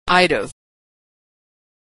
Reduction - Practice - Verb forms - Authentic American Pronunciation
reduced form